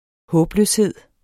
Udtale [ ˈhɔːbˌløːsˌheðˀ ]